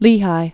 (lēhī)